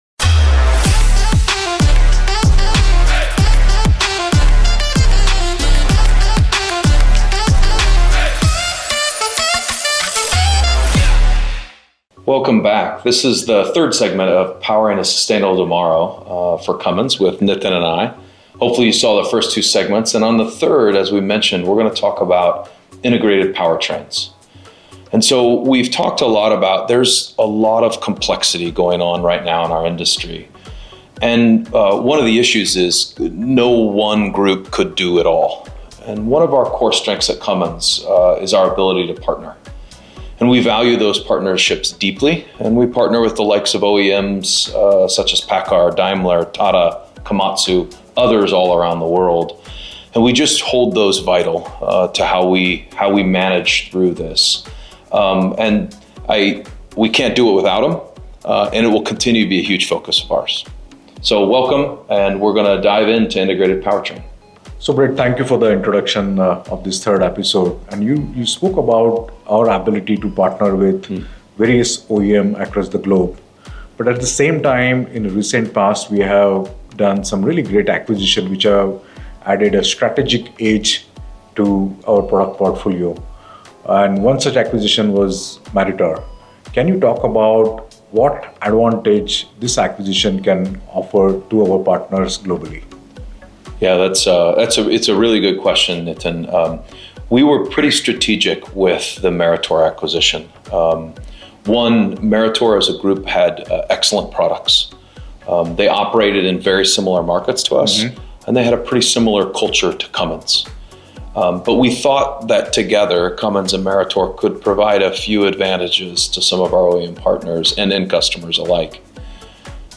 In this segment of our three-part conversation